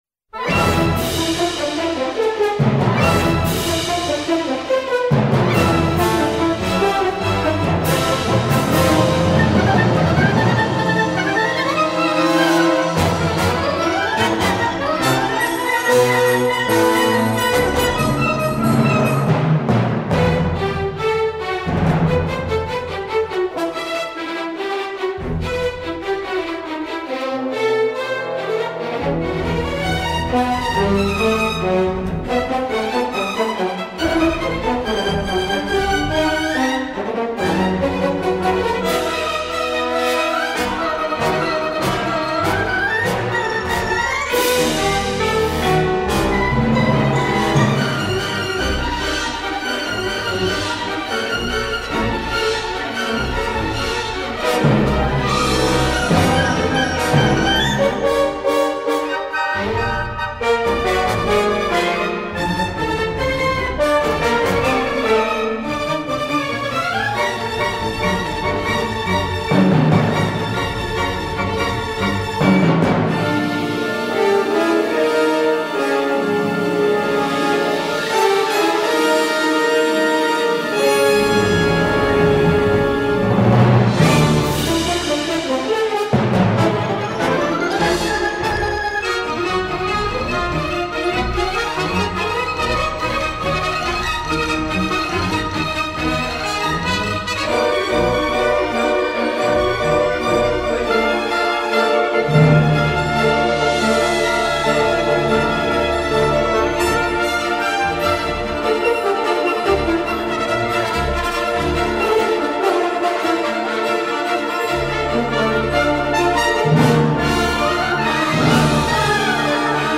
LugarClub Campestre